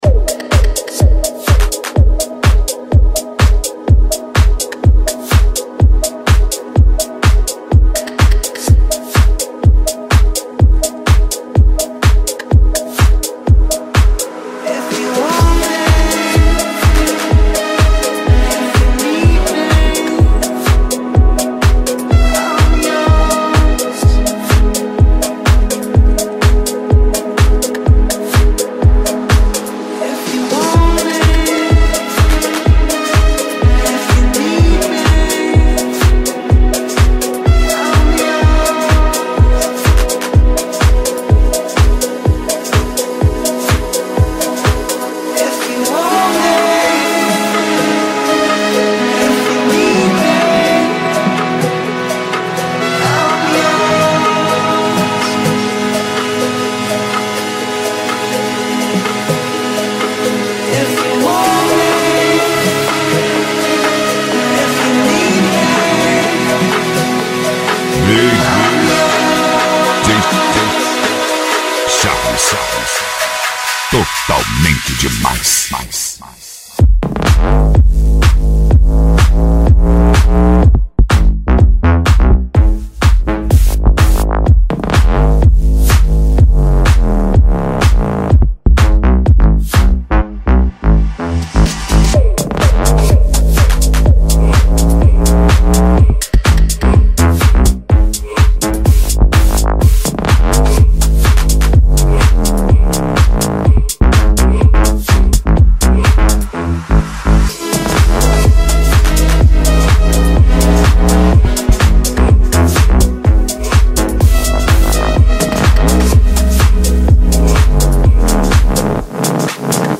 DEEP HOUSE.